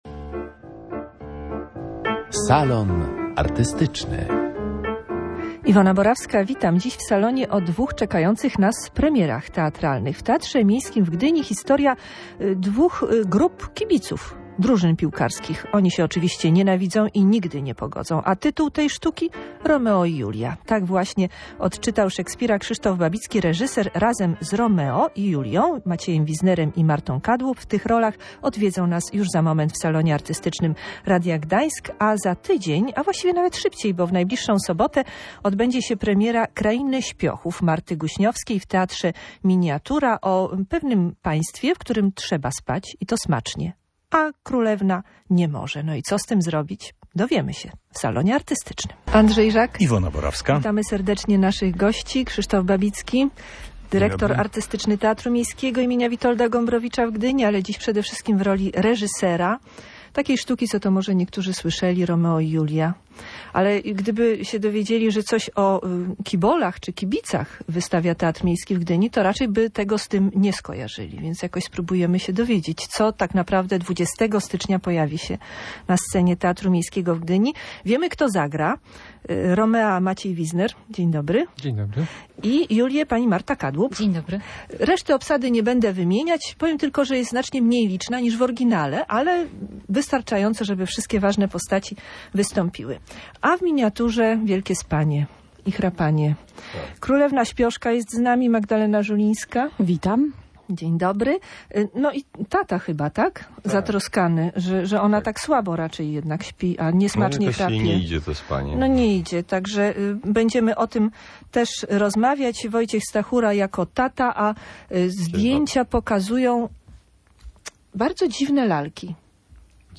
W Salonie Artystycznym rozmowy przedpremierowe z twórcami spektakli: „Kraina Śpiochów” w Teatrze Miniatura w Gdańsku oraz „Romeo i Julia” w Teatrze Miejskim w Gdyni.